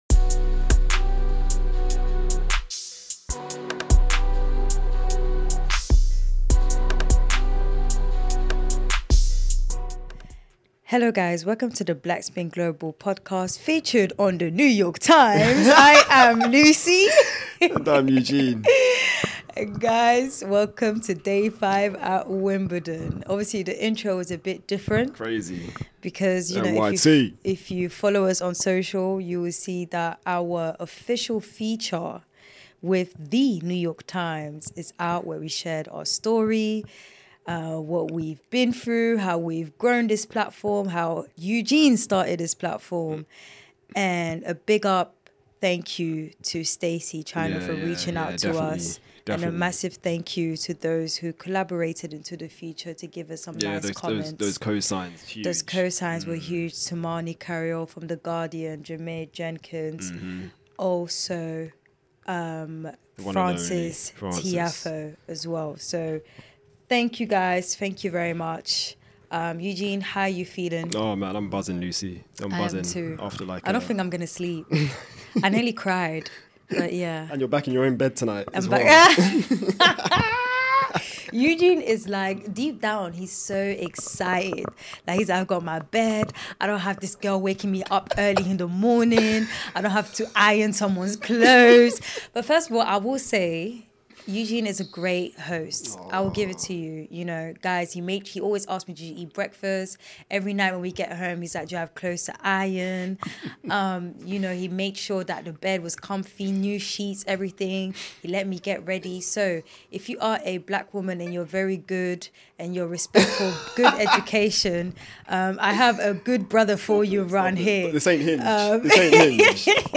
Listen out for interview snippets from Paolini, Keys, Monfils and Tiafoe.